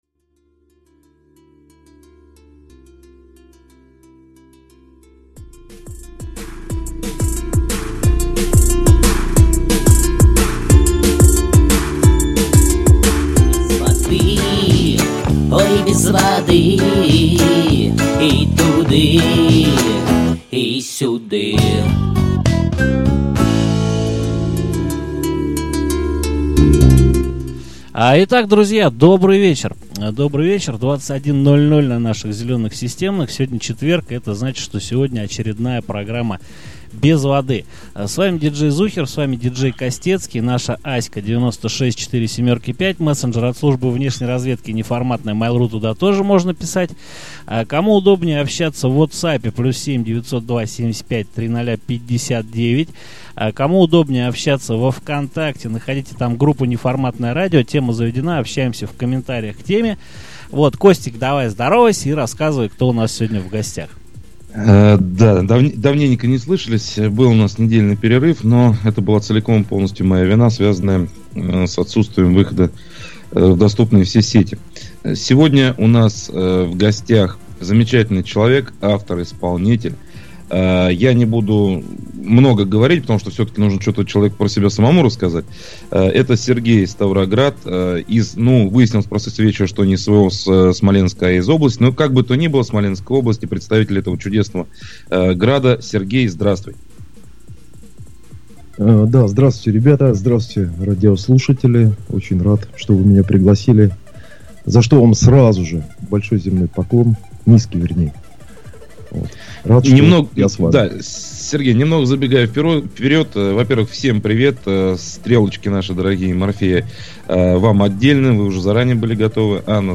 Первый, в истории Неформатного радио, разговор в прямом эфире, с человеком, близким к церкви.